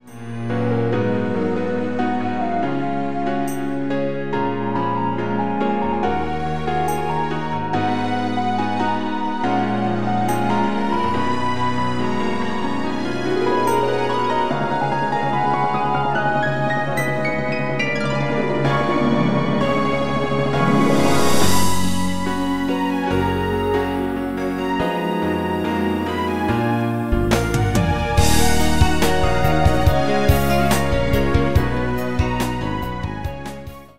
Bメロからサビに向かう「盛り上がり」の箇所です。
Bメロ内では「ピアノとストリングスのみ」でリズムといえば「鈴」の音が少し入ってくるだけです。
サビに向かってストリングスの音域が上がっていき、少しの盛り上がりを感じさせてくれます。
さらにティンパニ（オーケストラでよく見かける打楽器です）やハープ、極め付けにシンバルが派手に入ってきます。